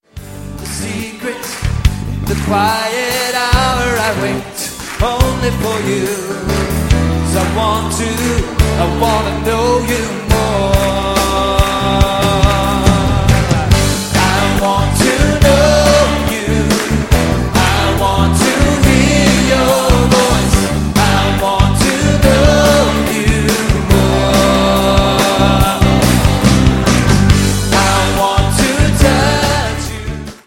25 modern worship favorites
• Sachgebiet: Praise & Worship